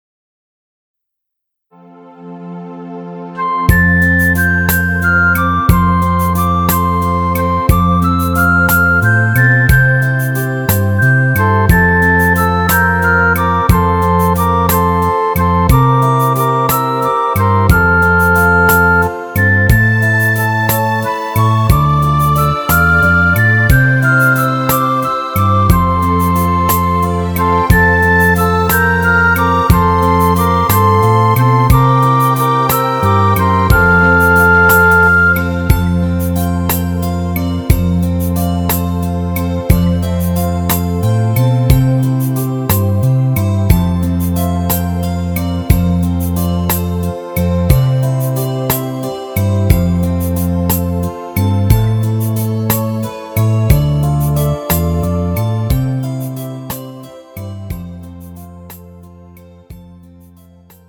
음정 F 키
장르 가요 구분 Pro MR